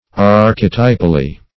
Search Result for " archetypally" : The Collaborative International Dictionary of English v.0.48: Archetypally \Ar"che*ty`pal*ly\, adv.
archetypally.mp3